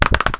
bot_move.wav